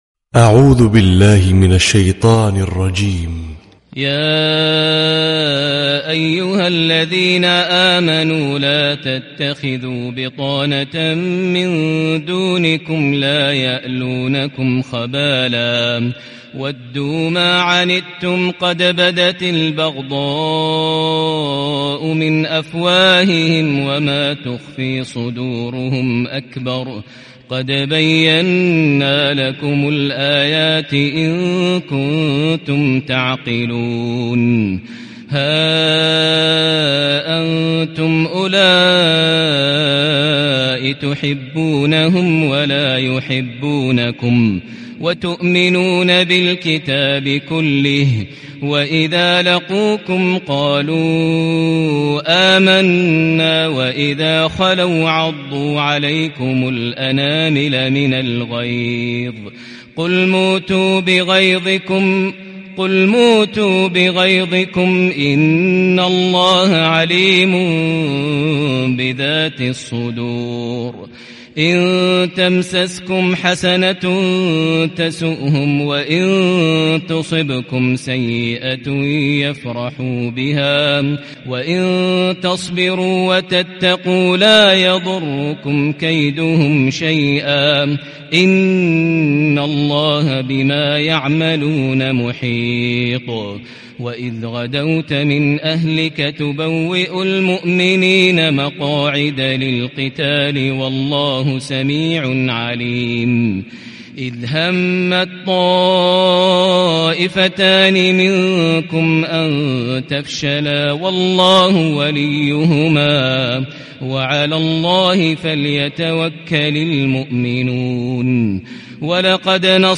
🌾•||تلاوة صباحية
*👤القارئ : ماهر المعيقلي*